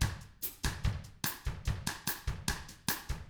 146BOSSAF1-R.wav